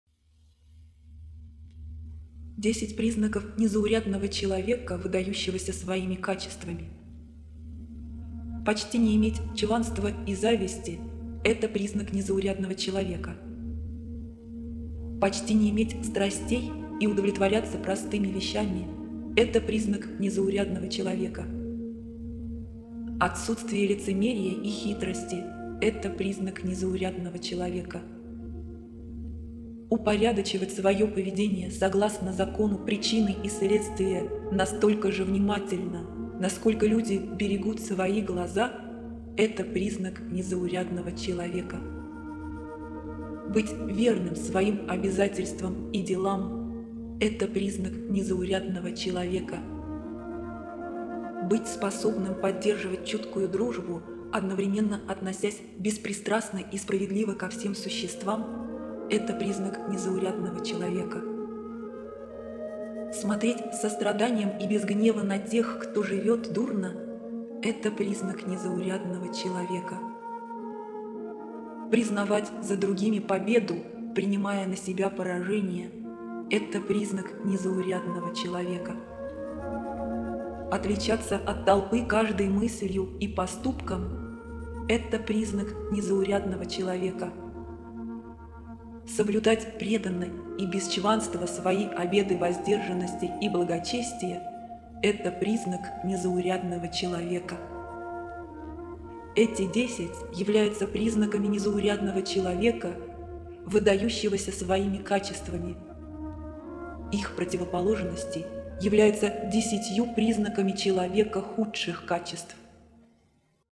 Аудиокнига: Драгоценные четки. Высший Путь Ученичества